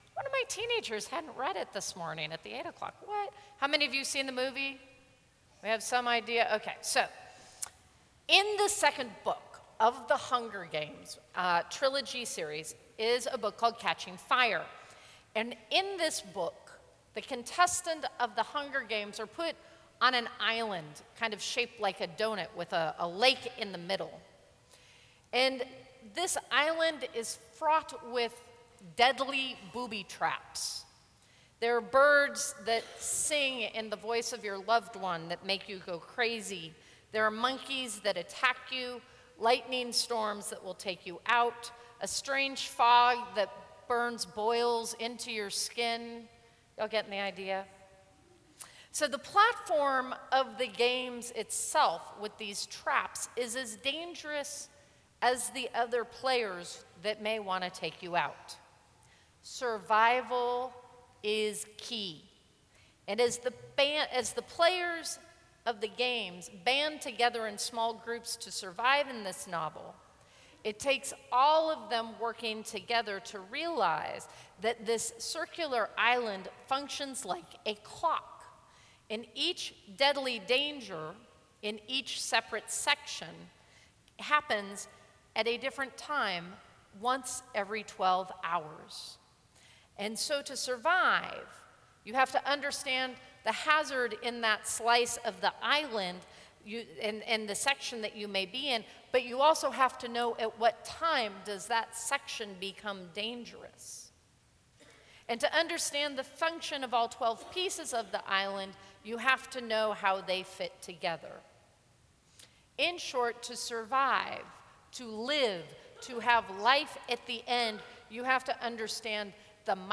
Sermons from St. Cross Episcopal Church February 16, 2014.